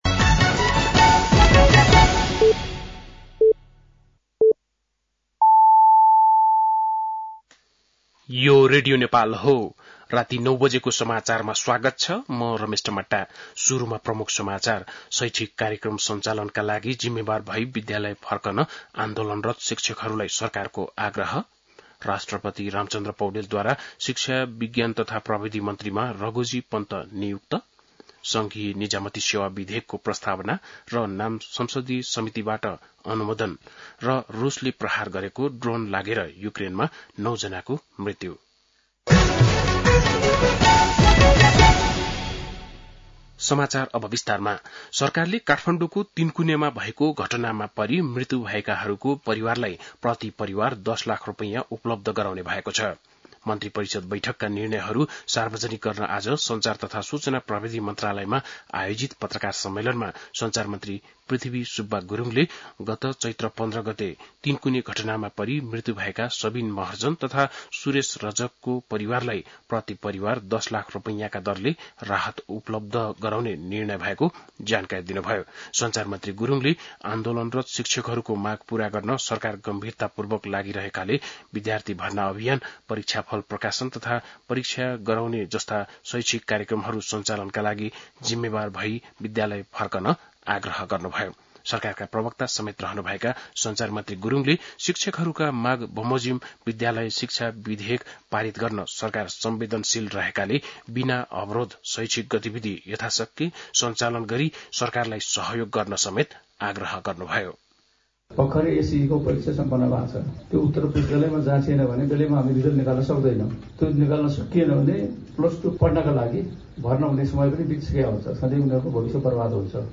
बेलुकी ९ बजेको नेपाली समाचार : १० वैशाख , २०८२
9-pm-nepali-news-5.mp3